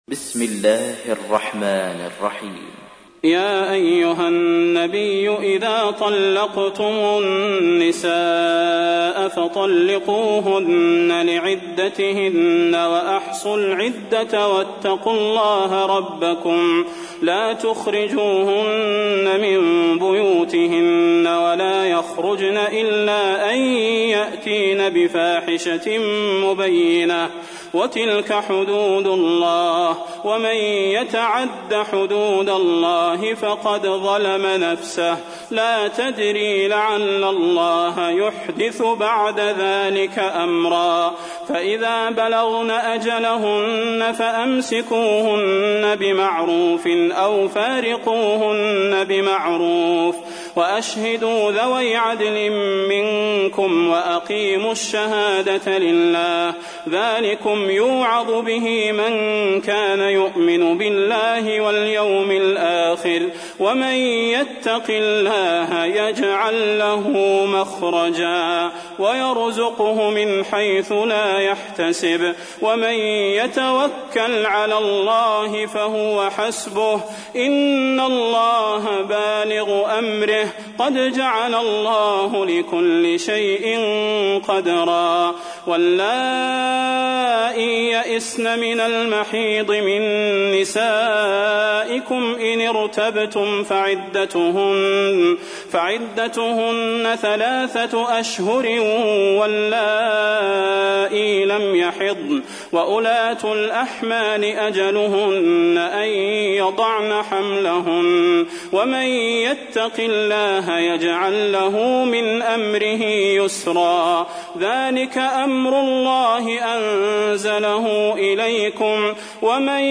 تحميل : 65. سورة الطلاق / القارئ صلاح البدير / القرآن الكريم / موقع يا حسين